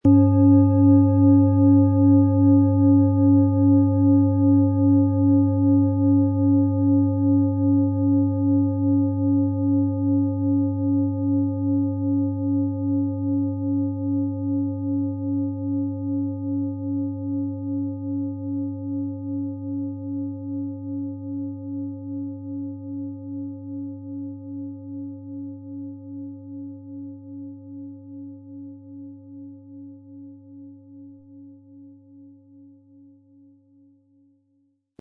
Sie möchten den schönen Klang dieser Schale hören? Spielen Sie bitte den Originalklang im Sound-Player - Jetzt reinhören ab.
Aber dann würde der ungewöhnliche Ton und das einzigartige, bewegende Schwingen der traditionellen Herstellung fehlen.
PlanetentonPluto & DNA (Höchster Ton)
MaterialBronze